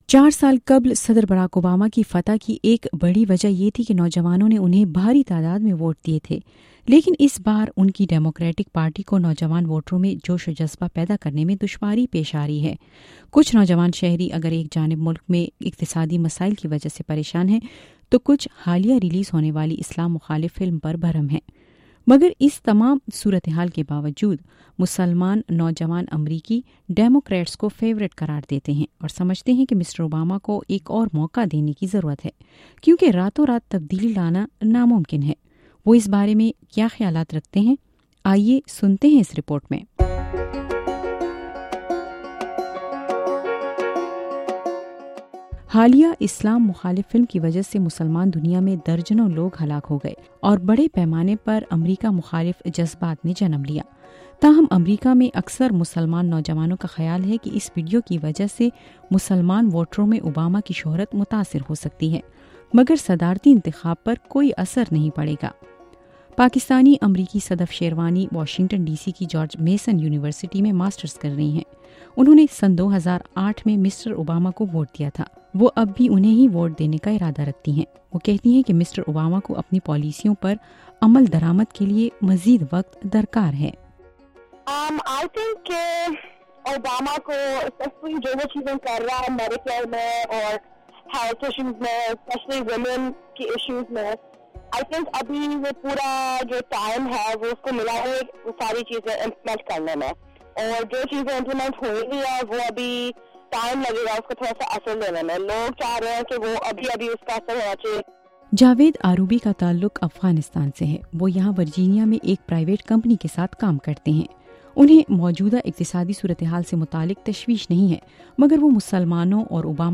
اس سلسلے میں ’وائس آف امریکہ‘نے کچھ پاکستانی نژاد امریکی نوجوانوں سے گفتگو کی۔